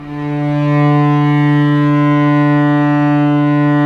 Index of /90_sSampleCDs/Roland LCDP13 String Sections/STR_Vcs II/STR_Vcs6 mf Amb